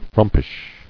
[frump·ish]